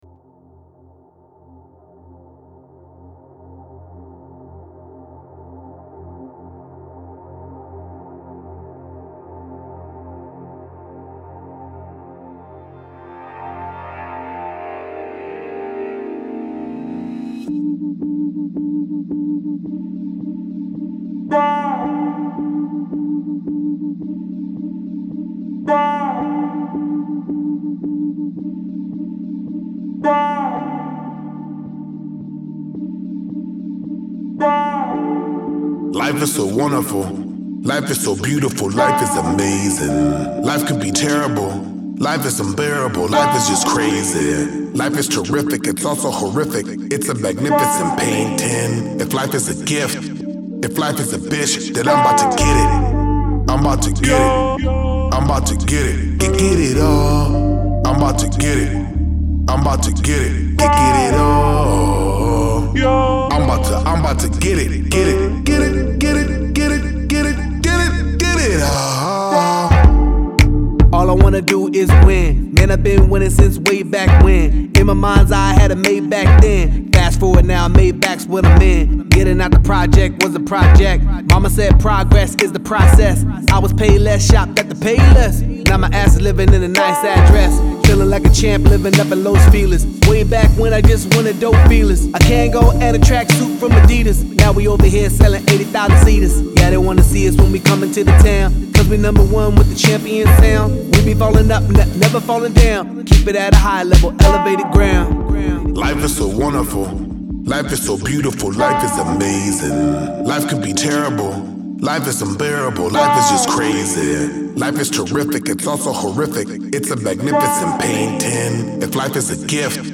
• Жанр: Корейские песни